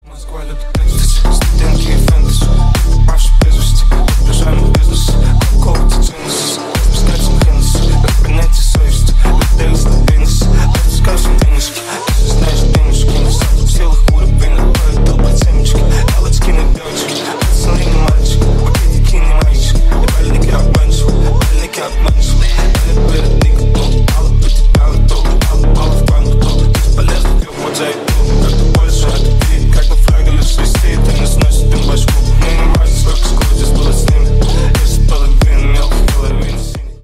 Ремикс
клубные # громкие